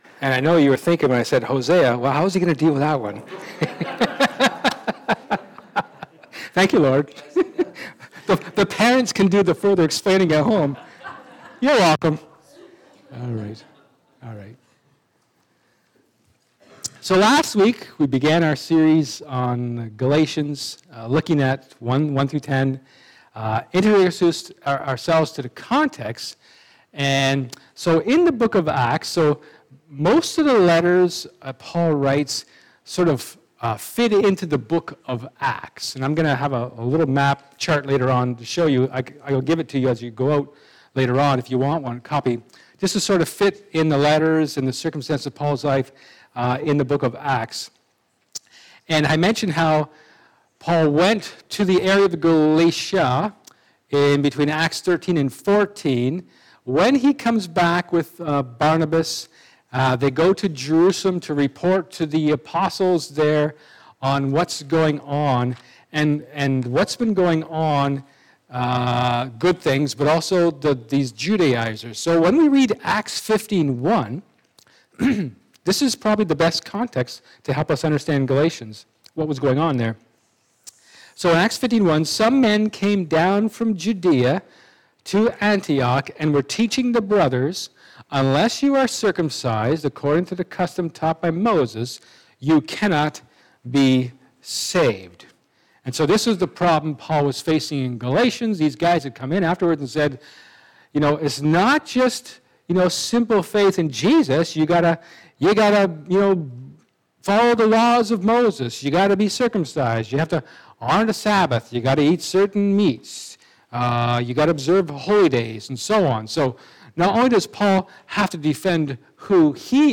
Galatians 1:11-24 Service Type: Sermon